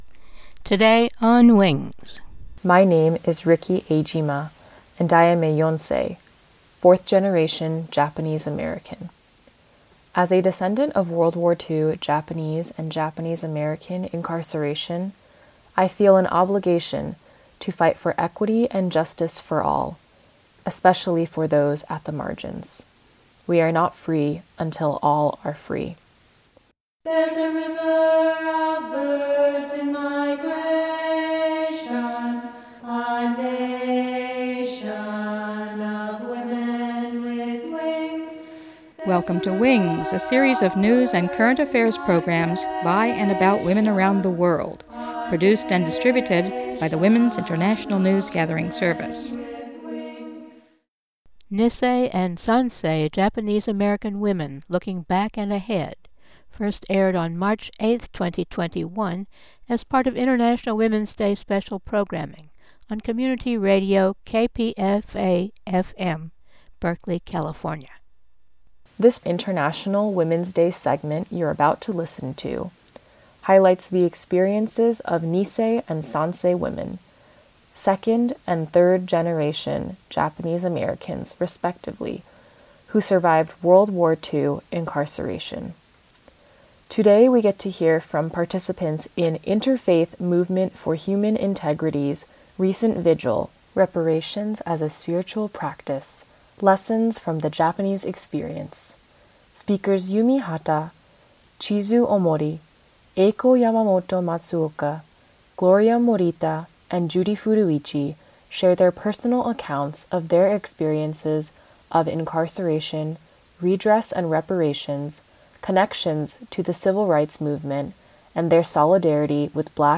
The elders re-tell their experiences of incarceration and transformation and connect those to the current movement for reparations for African American and Indigenous people of this country.